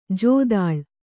♪ jōdāḷ